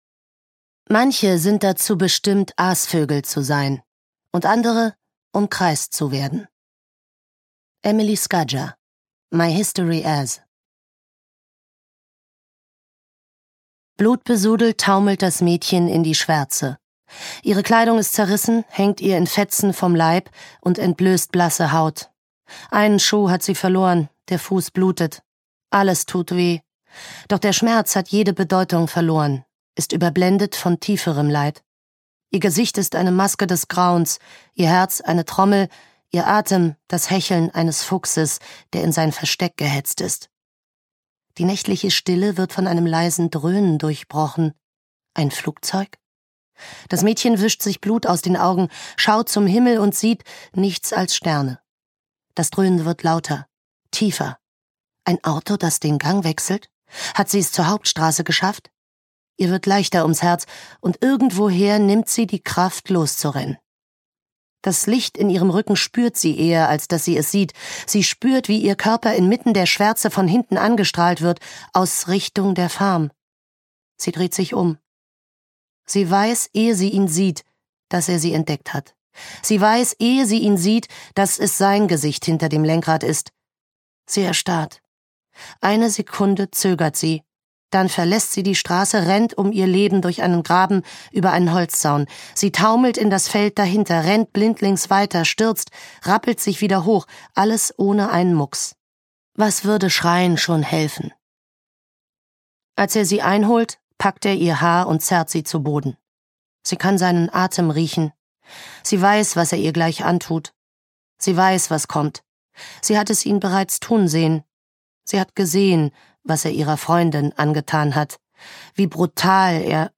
Ukázka z knihy
a-slow-fire-burning-de-audiokniha